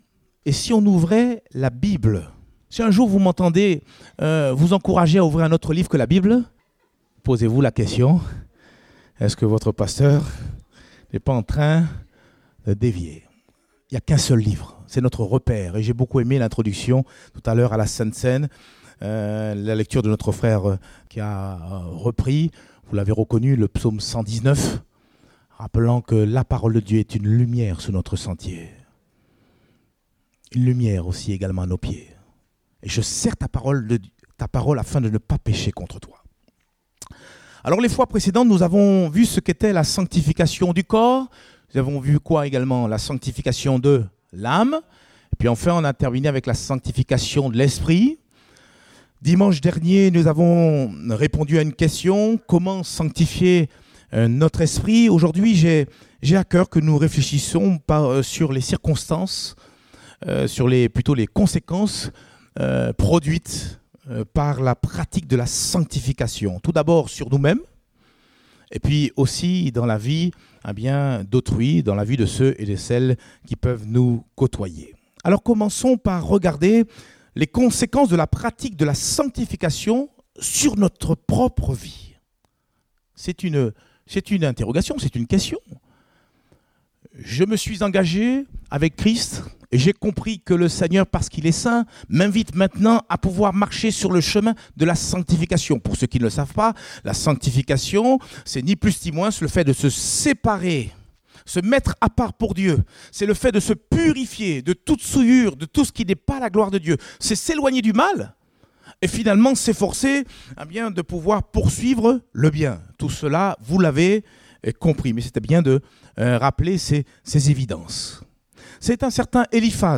Date : 12 mai 2019 (Culte Dominical)